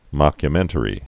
(mŏkyə-mĕntə-rē)